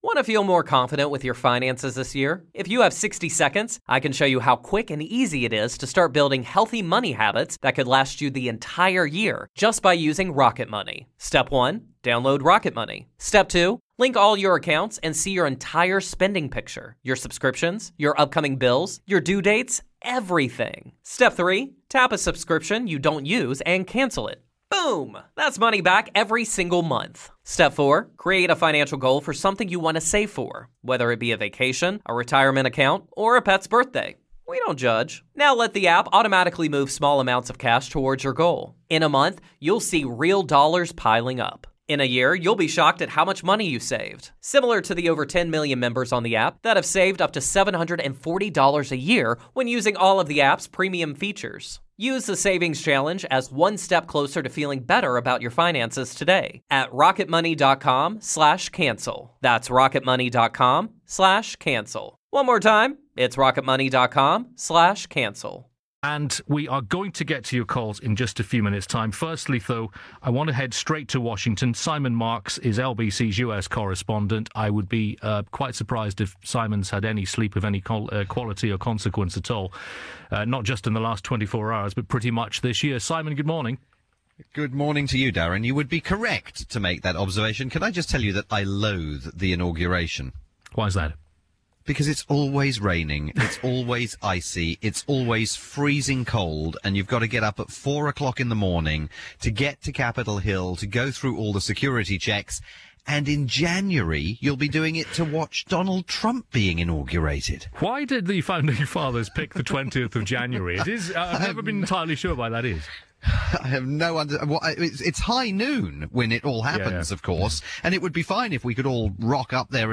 on LBC in the UK.